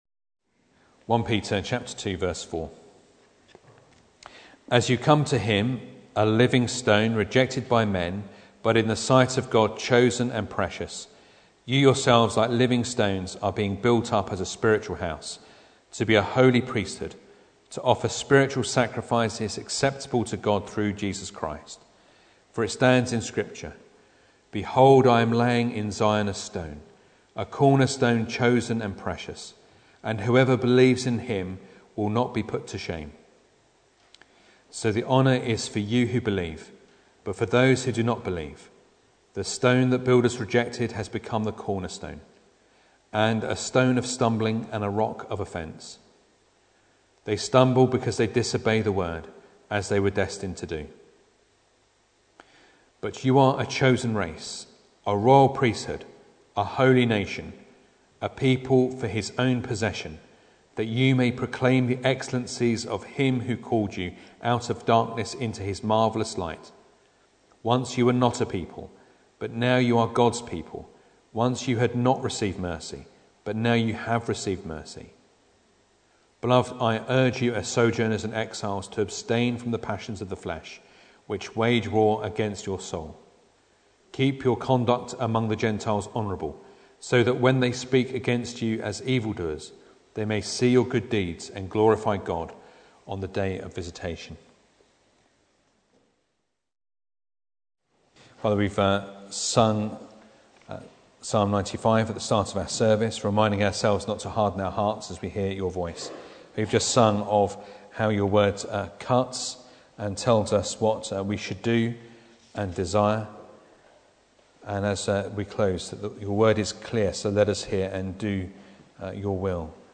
1 Peter 2:4-12 Service Type: Sunday Evening Bible Text